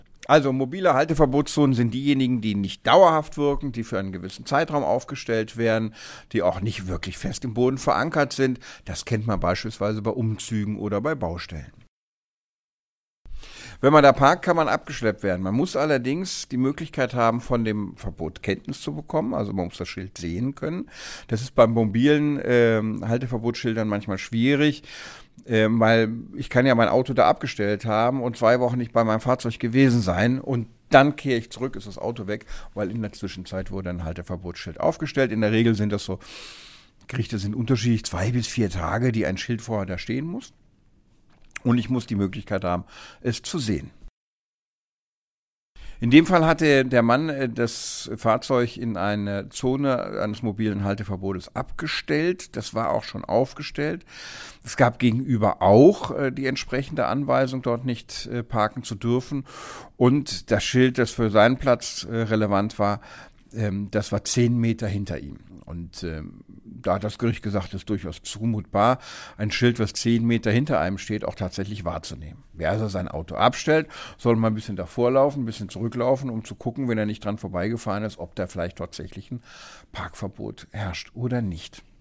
Kollegengespräch: Autofahrer müssen bei mobiler Halteverbotszone auf Verkehrsschilder achten